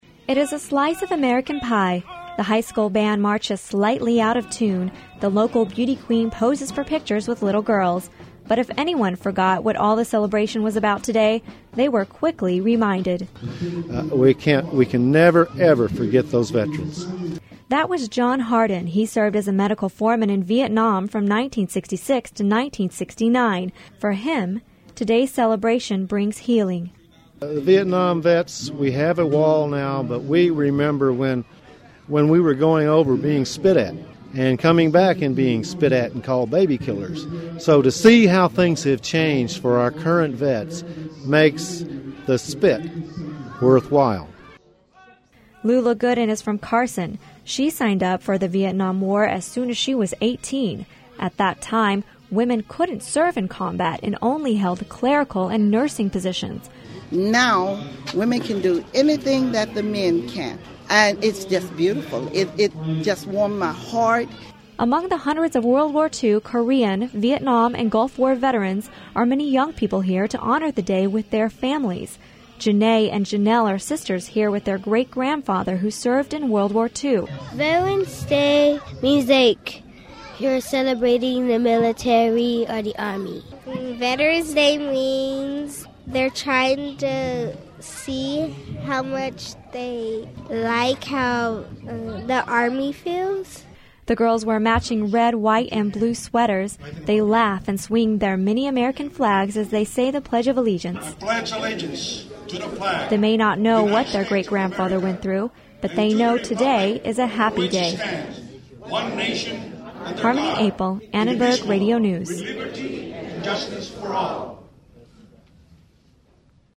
Hundreds of people gathered at the Veteran's Complex in Carson today. People came for different reasons to celebrate what Veteran's Day means for them.